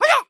Звук удара кием в каратэ